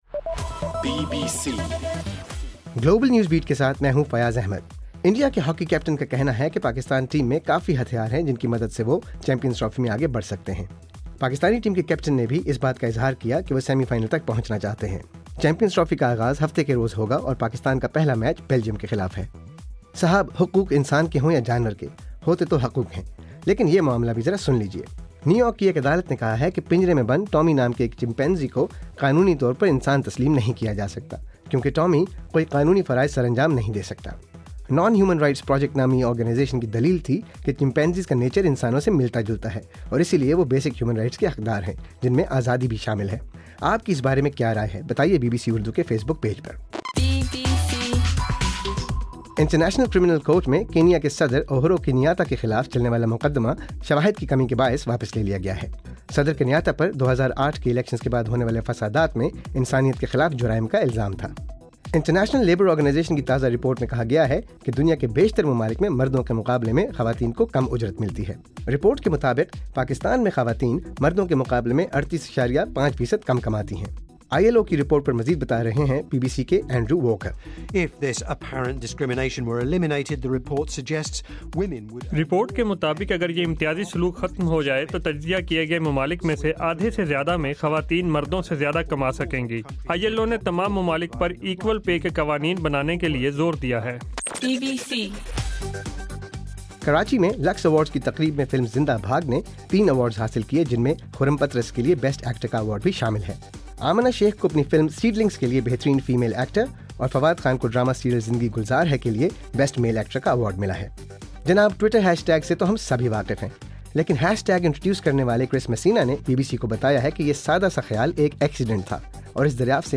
دسمبر 5: رات 10 بجے کا گلوبل نیوز بیٹ بُلیٹن